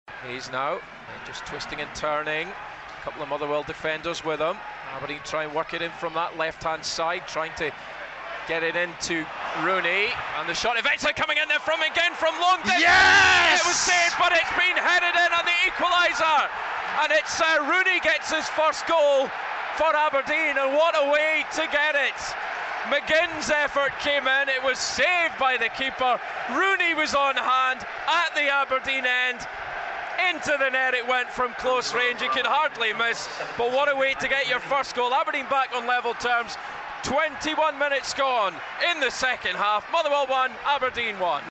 The lads provide live commentary of all Aberdeen games home and away on Red TV, in association with Northsound 1.